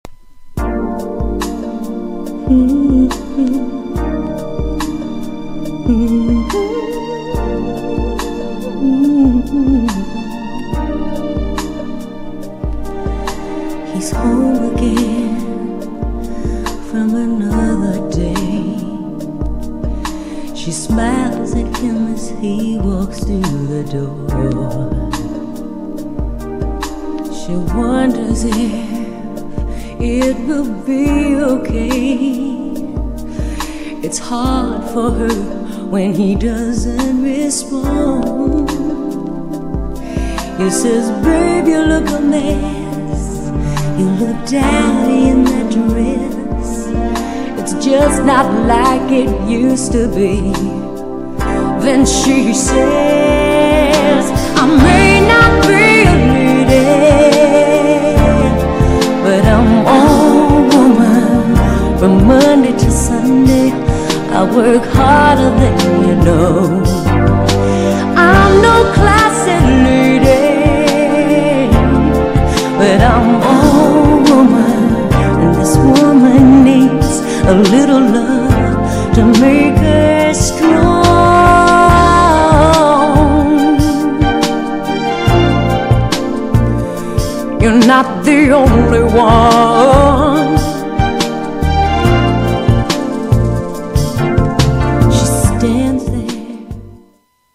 優しく切なく、それでいて芯のあるエモーショナルな
GENRE House
BPM 106〜110BPM